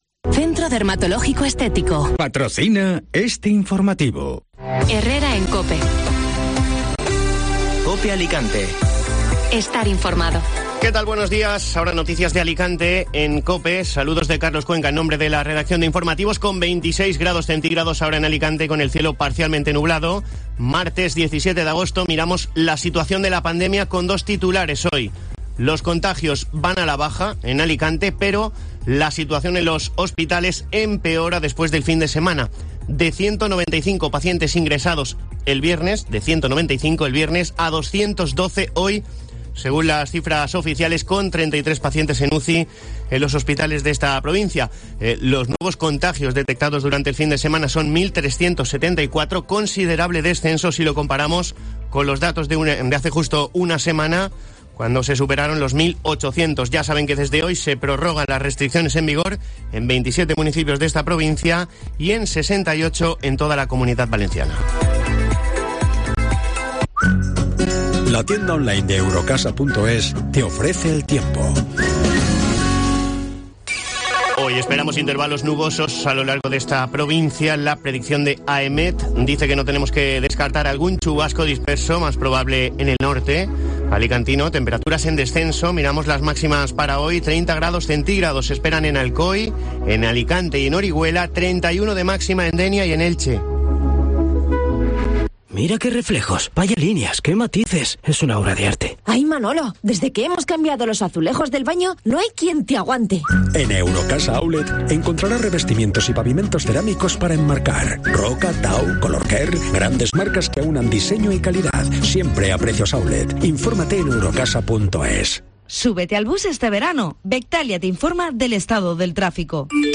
Informativo Matinal Alicante (Martes 17 de agosto)